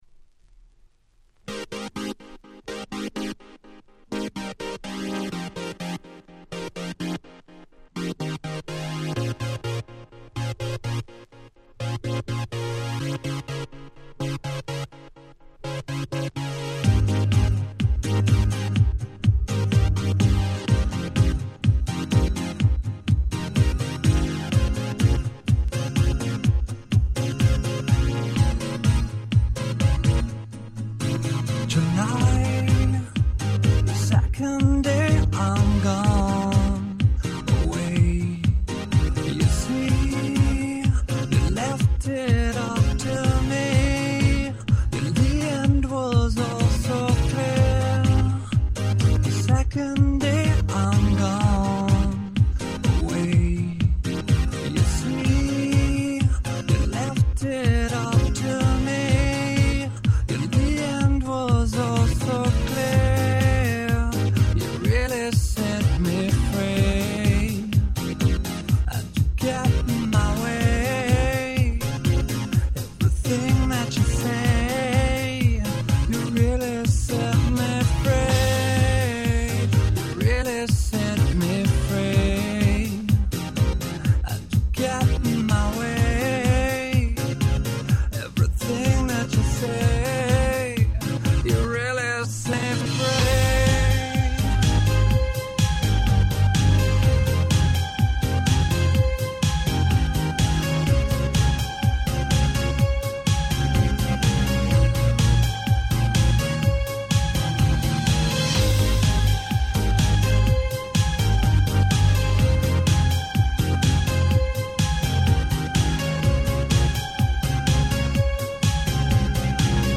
所謂「乙女系House」の最高峰。